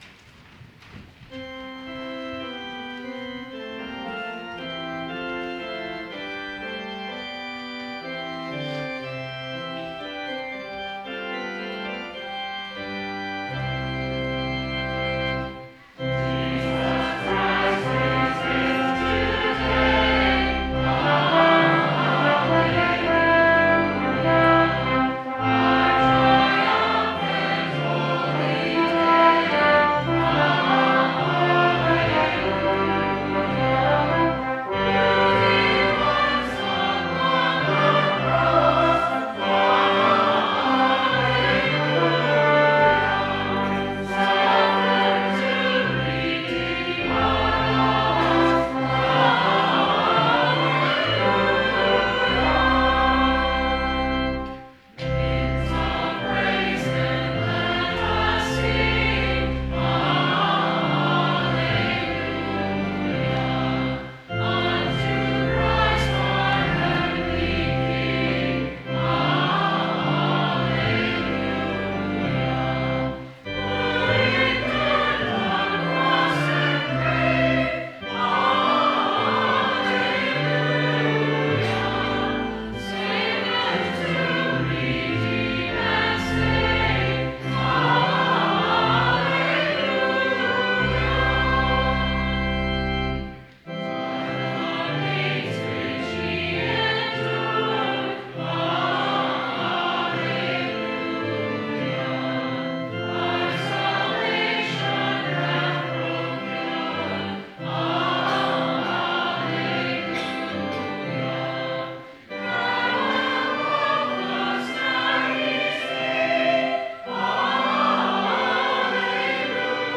Full Sermon Draft Download Biblical Text: Text: Acts 11:1-18, John 16:12-15 Change in the church is always a contentious issue.